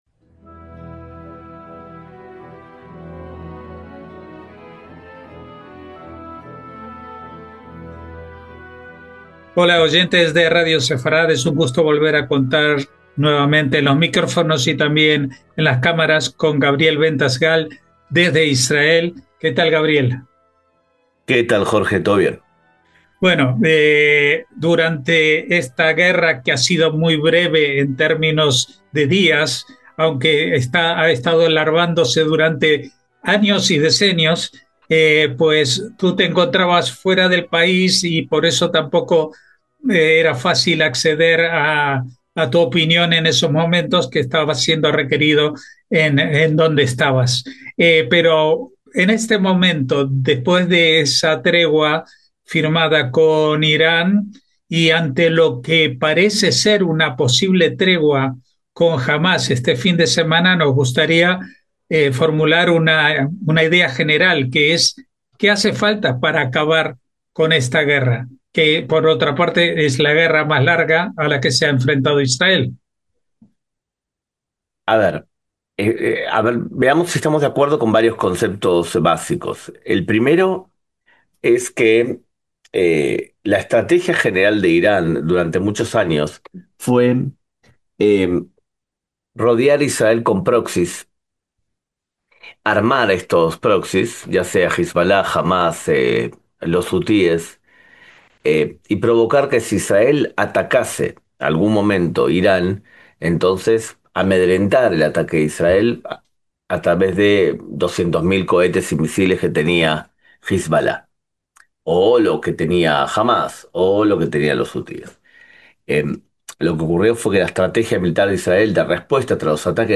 Ahora, ya de vuelta en casa, pudimos entrevistarlo y hablar de los posibles escenarios próximos para Israel, entre un posible cese de fuego con Hamás en Gaza (que no necesariamente sería el fin de esa guerra), dependiendo que quíen vaya a gobernar después del intercambio de rehenes por presos, quién reparta la ayuda humanitaria y quién se encargue de combatir el terrorismo en la Franja. Ello no es óbice para que, paralelamente, se inicien acuerdos de paz con países musulmanes sunitas no radicales.